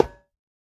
latest / assets / minecraft / sounds / block / iron / break5.ogg
break5.ogg